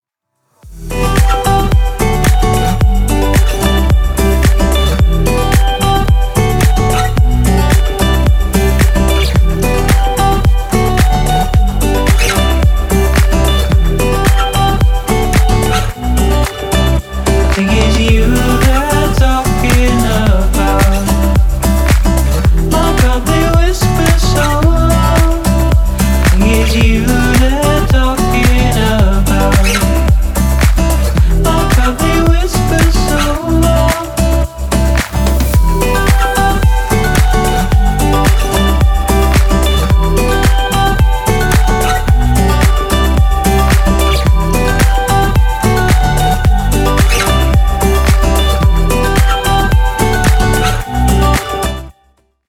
• Качество: 320, Stereo
гитара
Electronic
Стиль: chillout